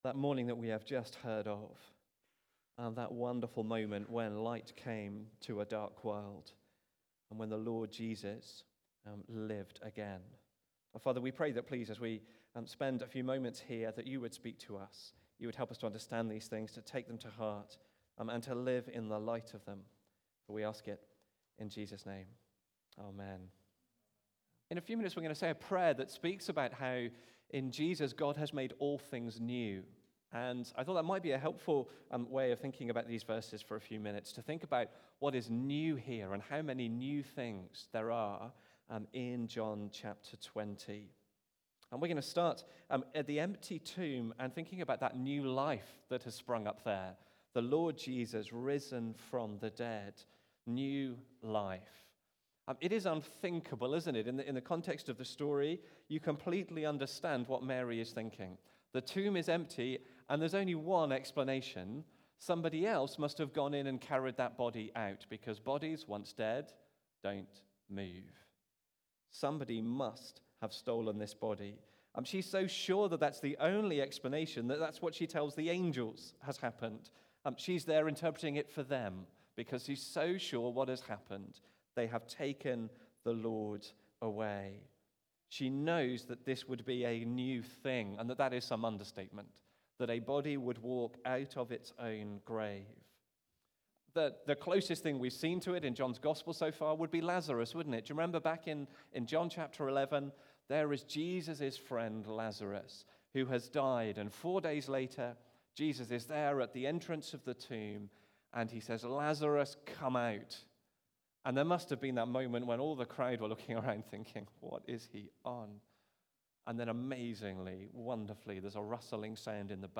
All Is New Again (John 20:1-18) from the series Easter 2025. Recorded at Woodstock Road Baptist Church on 20 April 2025.